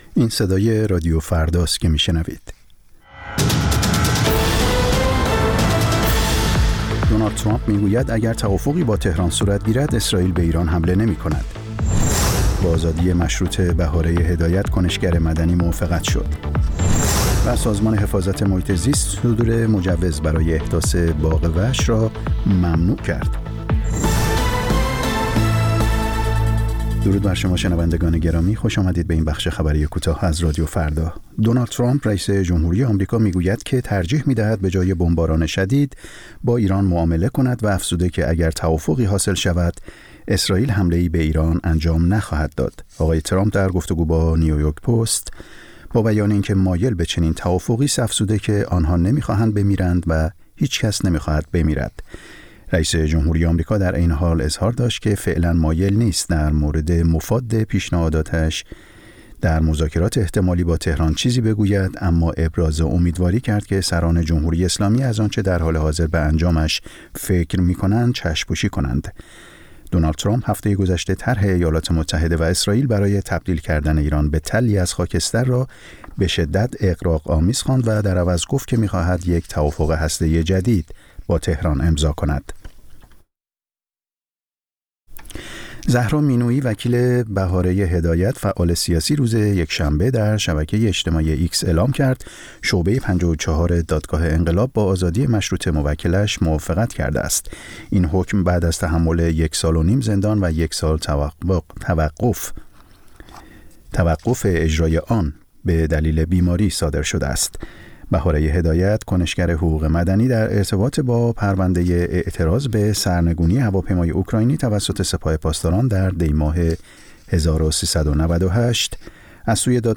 سرخط خبرها ۲۱:۰۰